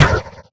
gigoong_shot.wav